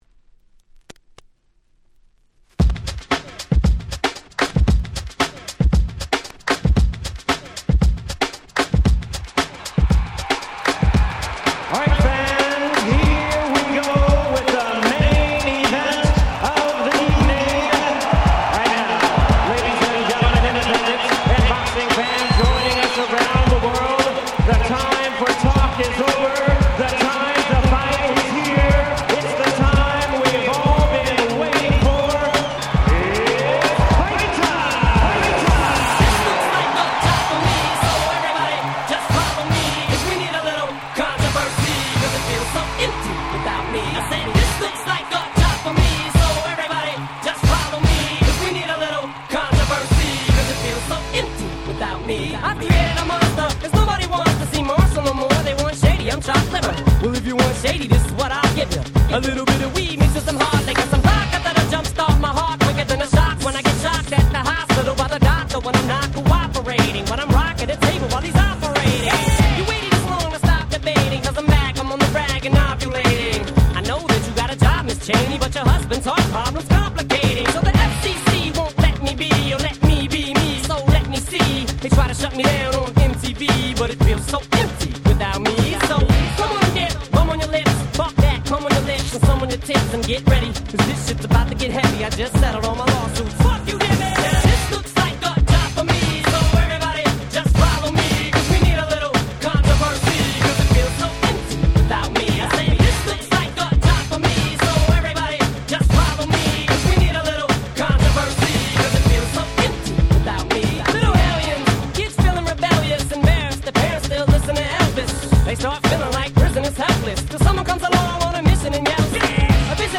04' Nice Mush Up !!
どちらもキラーチューンなので盛り上がりは確実！！